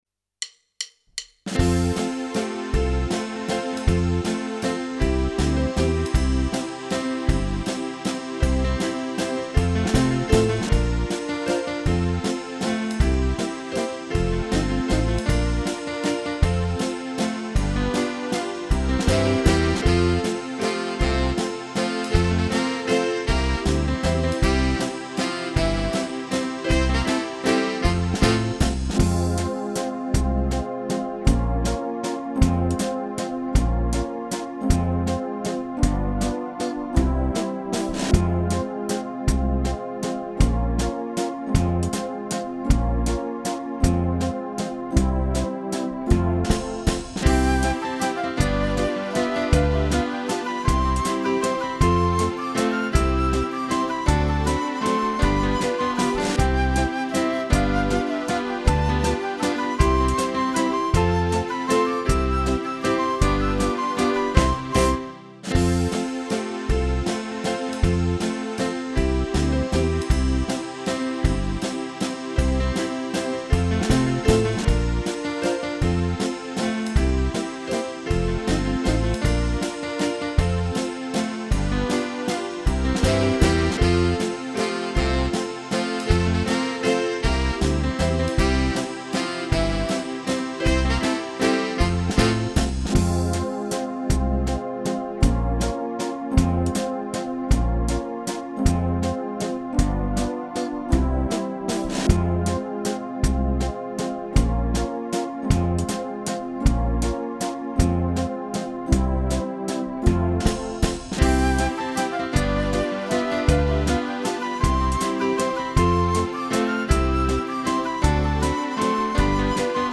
Java